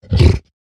sounds / mob / hoglin / idle1.ogg